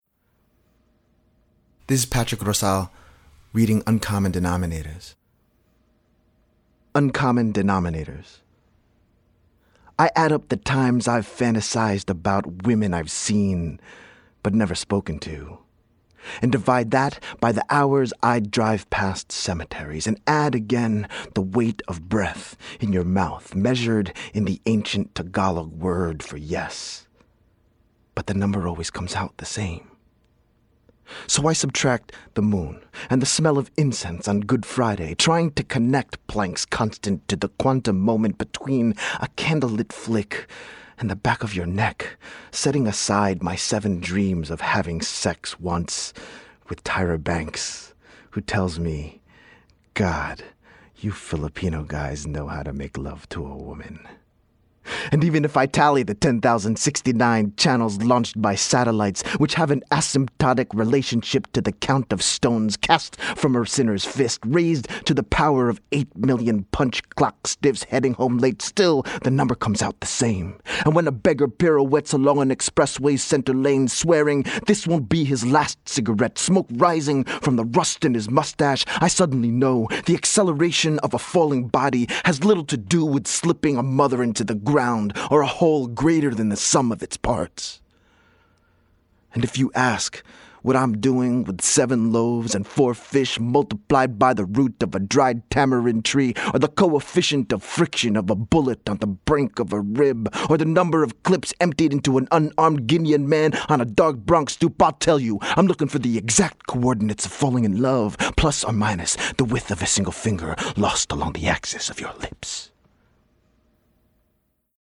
reads his poem